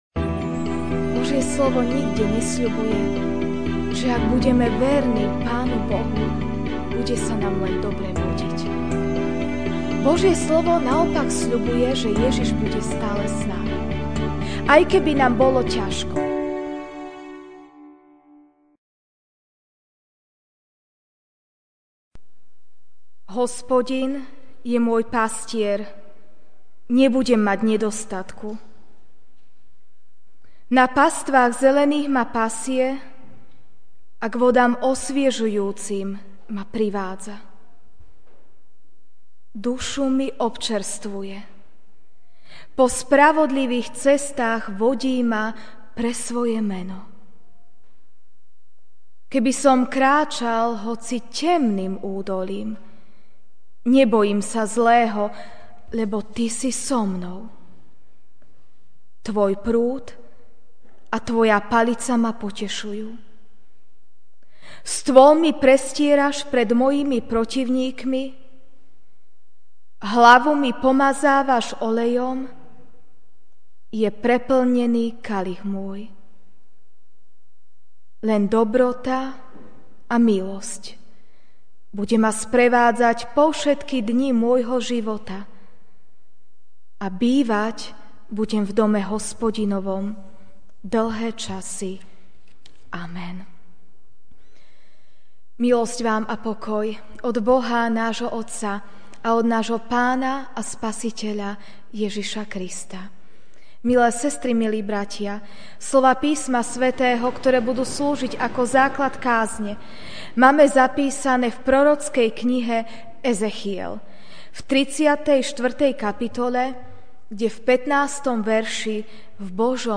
apr 30, 2017 Boh – Dobrý pastier MP3 SUBSCRIBE on iTunes(Podcast) Notes Sermons in this Series Ranná kázeň: Boh - Dobrý pastier (Ezechiel 34, 15) Ja sám budem pásť svoje stádo a ja mu umožním odpočívať - znie výrok Hospodina, Pána.